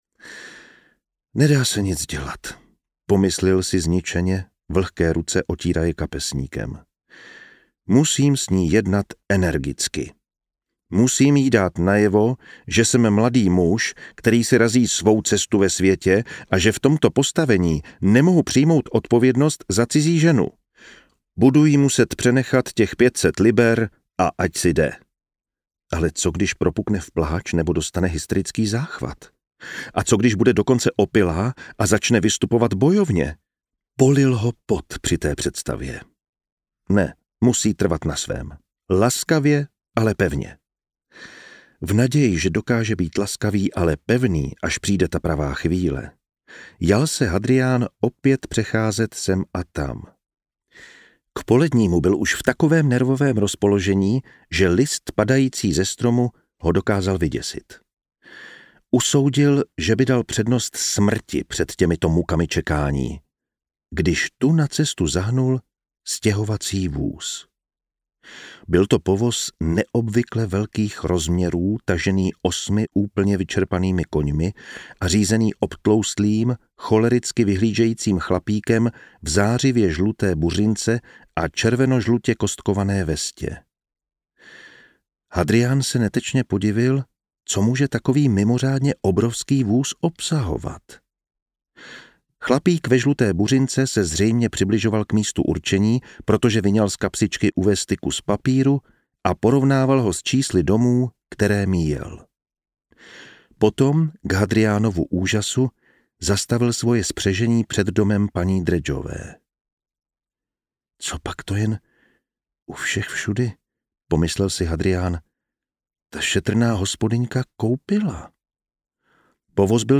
Růženka je z příbuzenstva audiokniha
Ukázka z knihy
• InterpretJan Vondráček